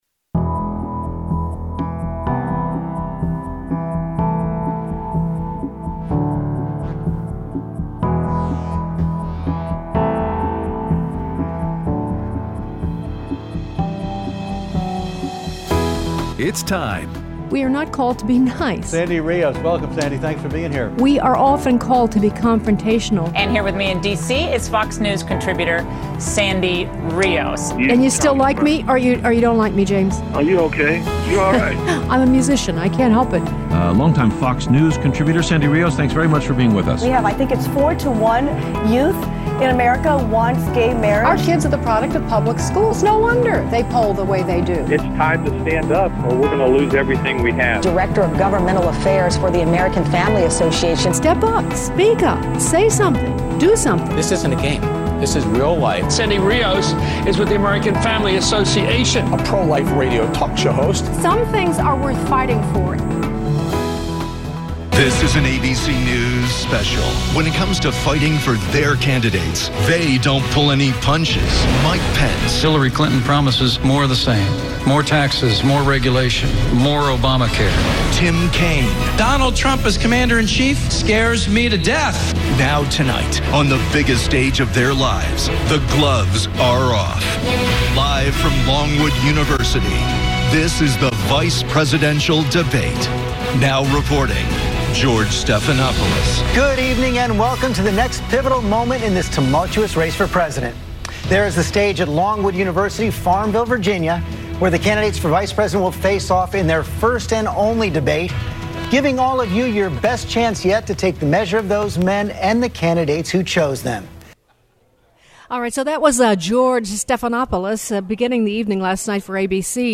Live at the AFA Retreat at The Cove with Analysis of the VP Debate